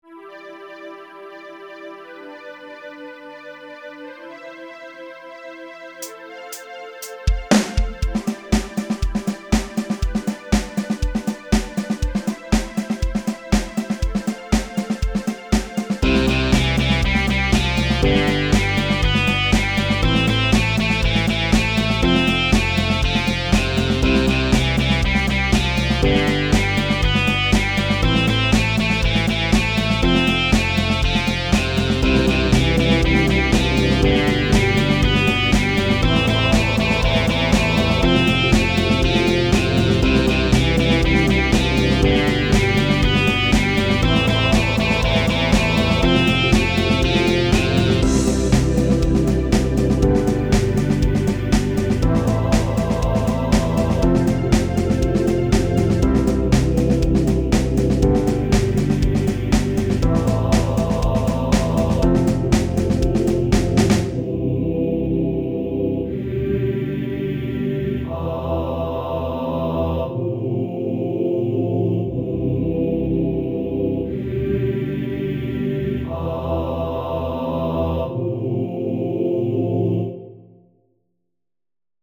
Home > Music > Pop > Bright > Smooth > Laid Back